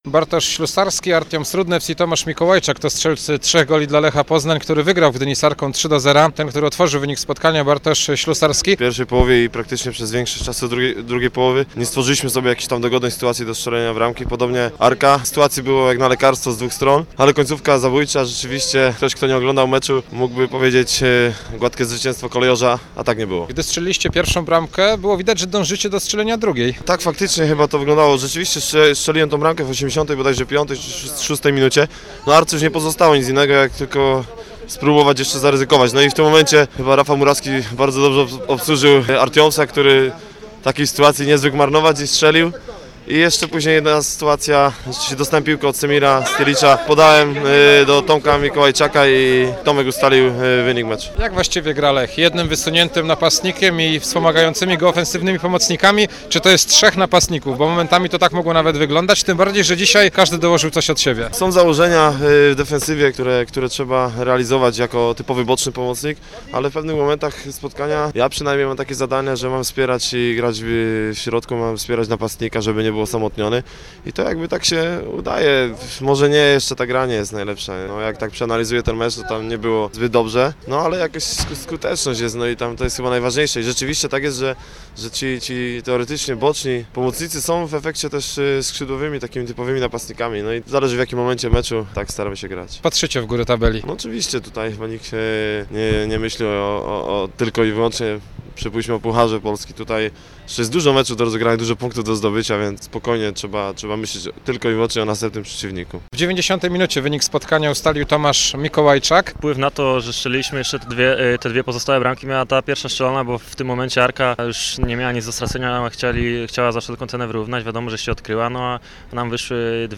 c8cynfgn0ko8j70_rozmowy_po_meczu_lech.mp3